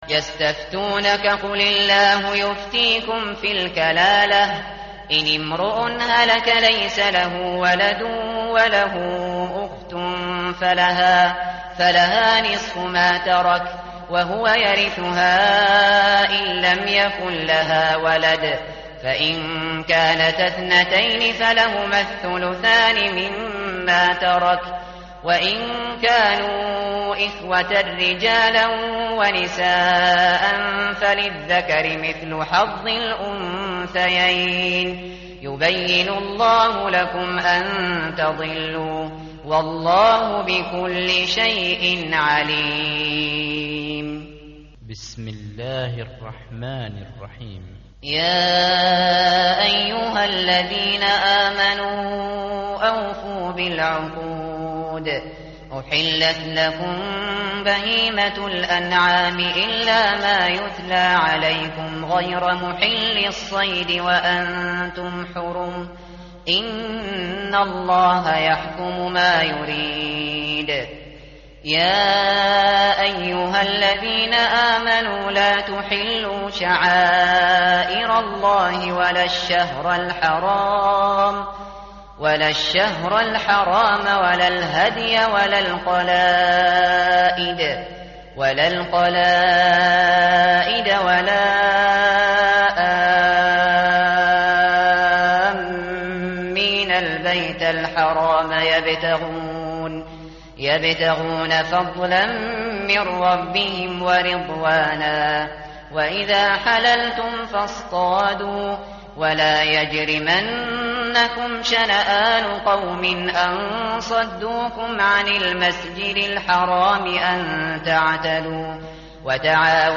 متن قرآن همراه باتلاوت قرآن و ترجمه
tartil_shateri_page_106.mp3